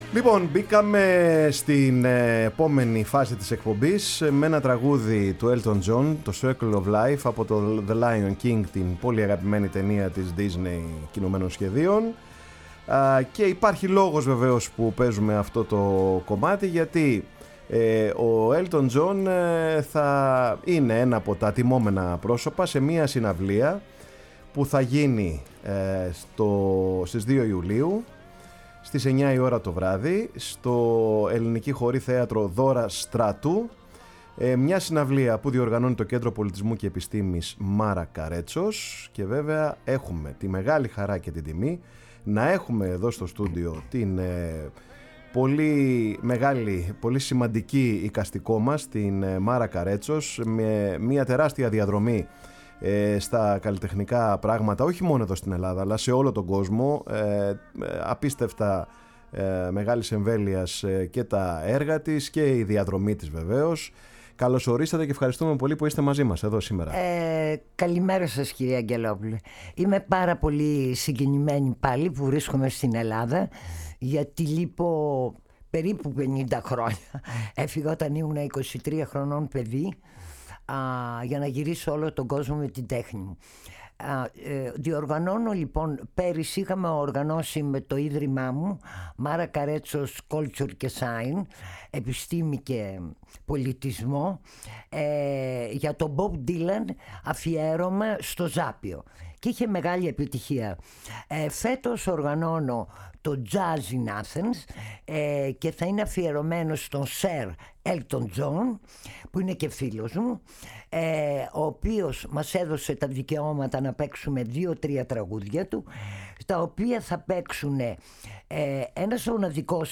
φιλοξένησε σήμερα στο στούντιο